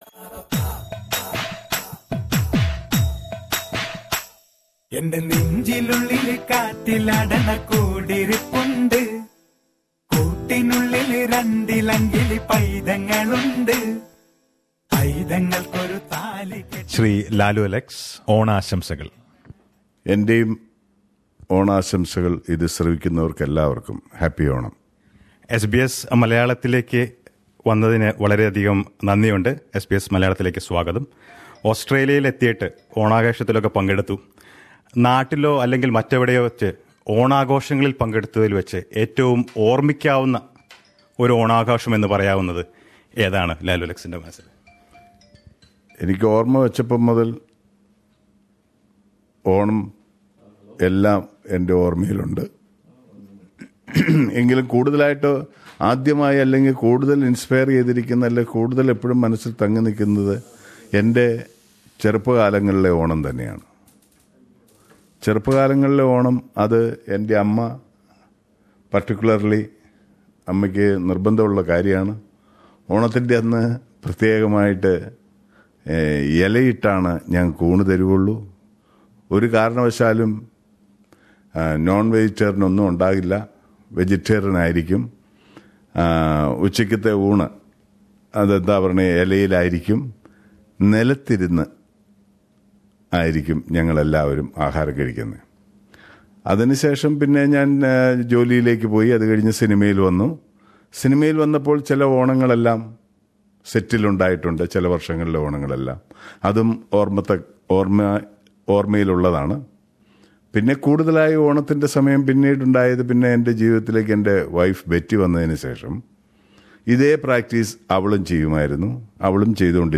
On the day of Thiruvonam, Lalu Alex spent some time with SBS Malayalam, talking about the Onam celebrations in his memory and various trends in the Malayalam movie industry. Let us listen to the interview...